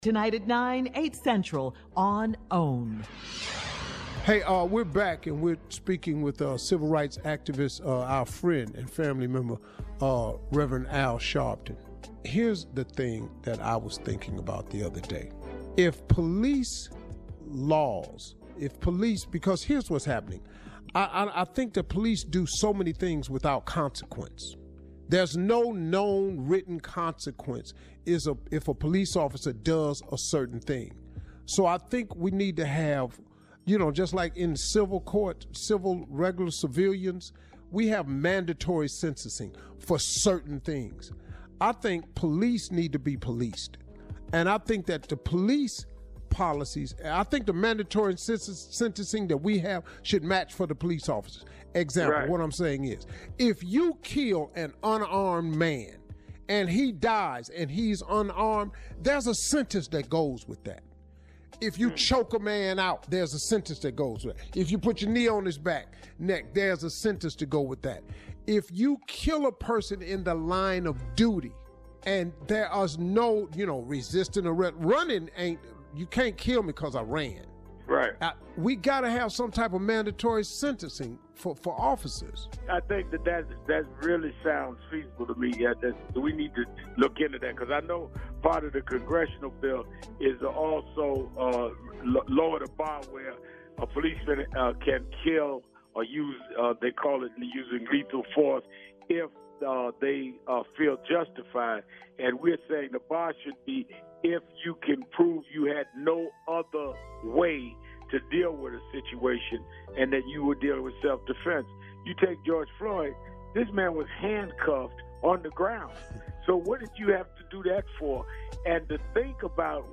Sharpton-Interview-2.mp3